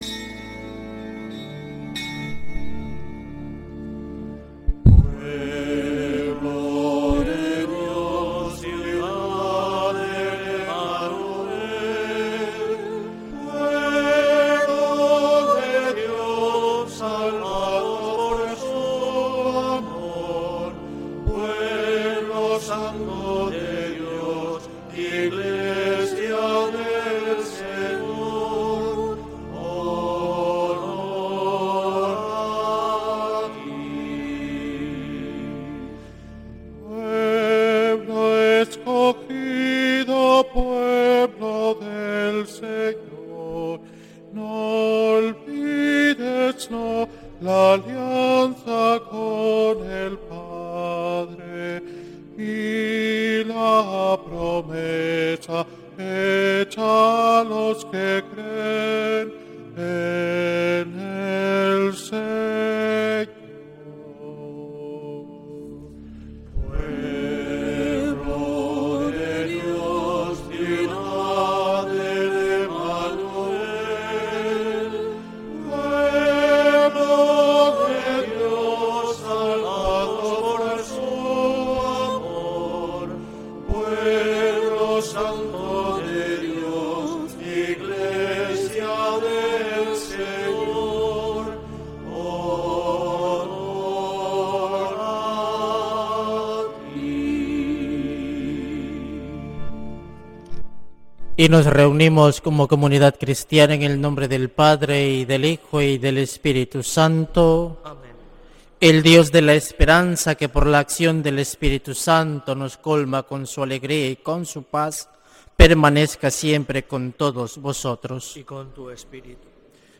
Santa Misa desde San Felicísimo en Deusto, domingo 5 de octubre de 2025